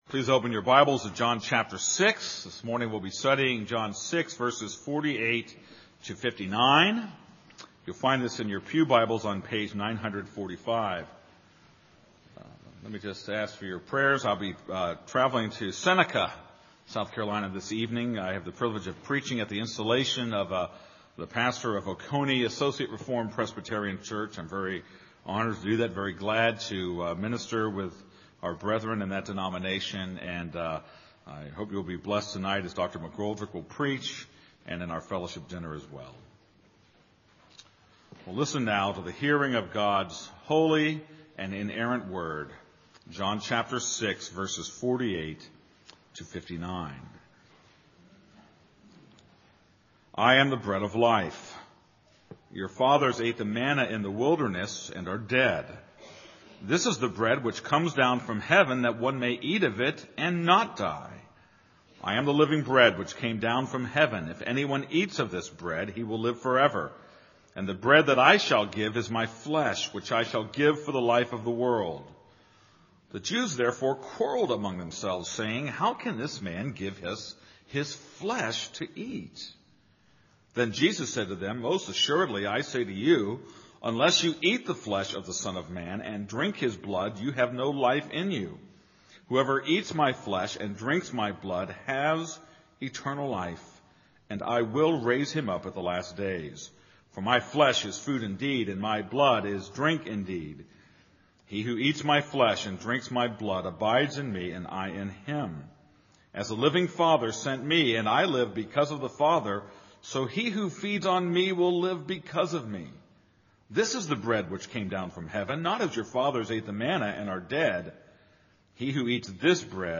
This is a sermon on John 6:48-59.